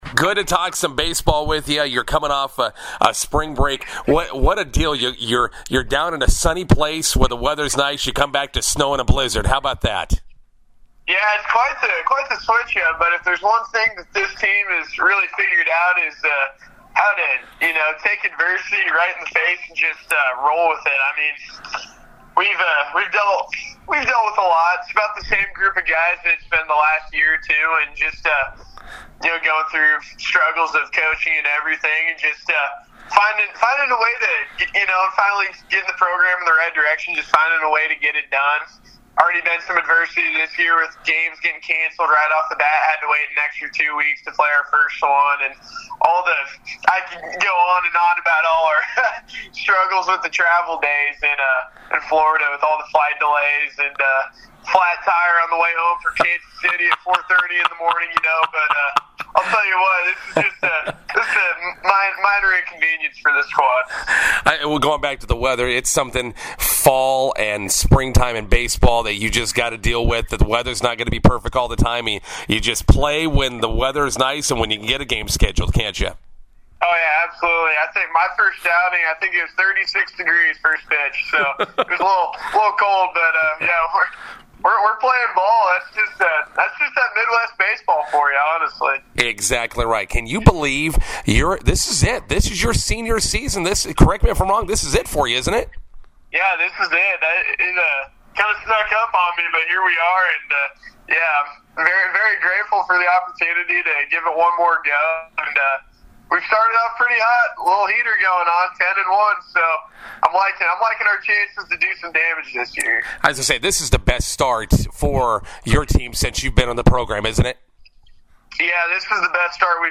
INTERVIEW: Nebraska Wesleyan baseball off to an 10-1 start.